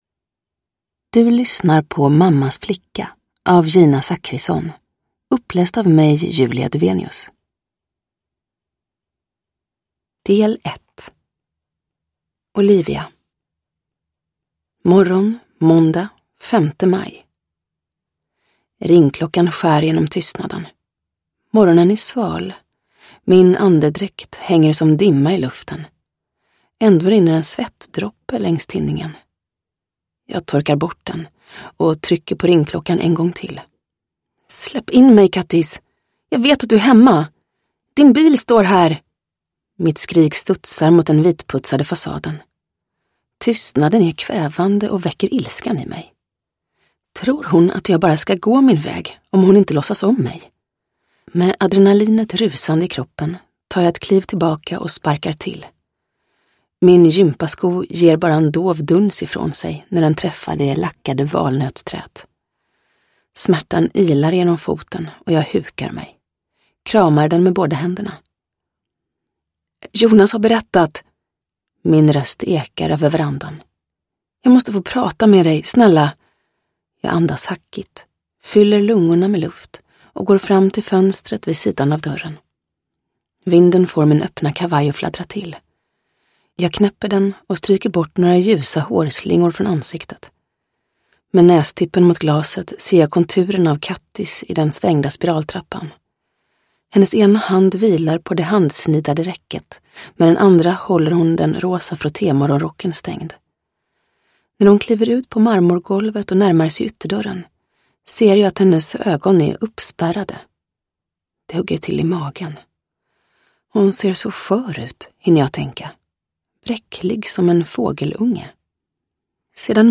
Mammas flicka – Ljudbok